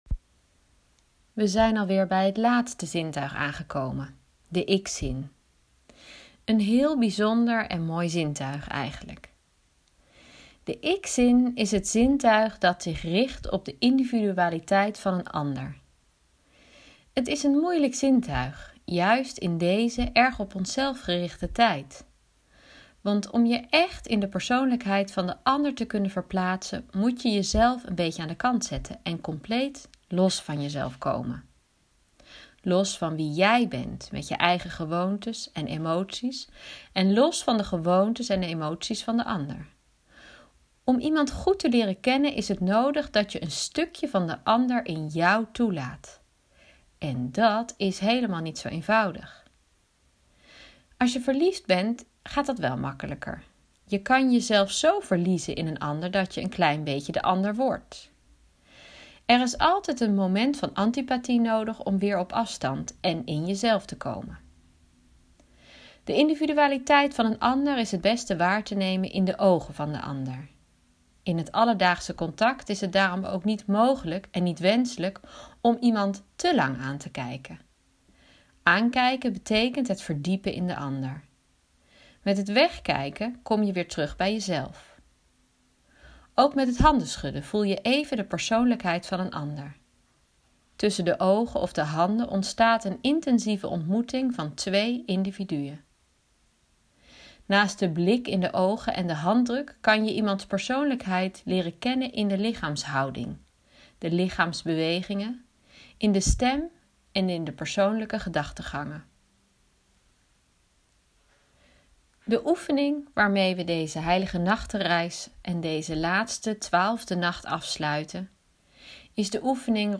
Audiobericht: